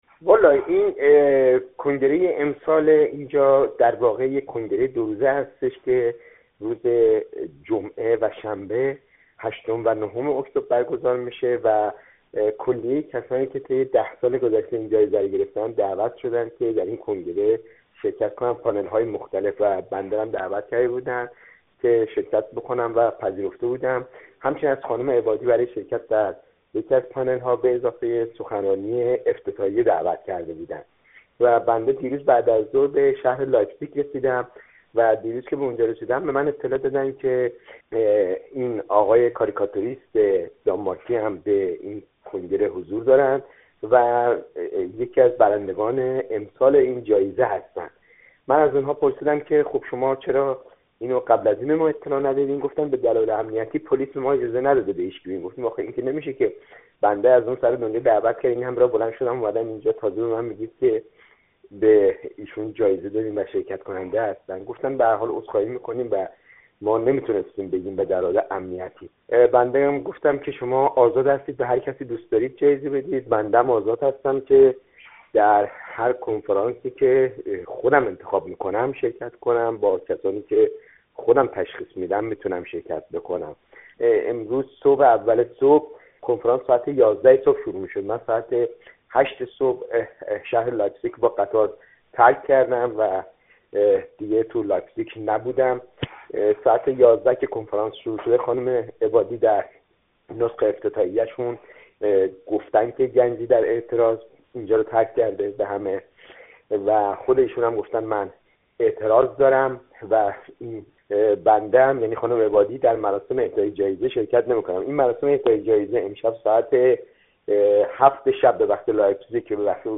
گفت‌وگوی اکبر گنجی با رادیو فردا در مورد اعتراض به اعطای جایزه‌ به کاریکاتوریست دانمارکی